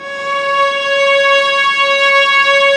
Index of /90_sSampleCDs/Roland LCDP13 String Sections/STR_Violas II/STR_Vas4 Amb p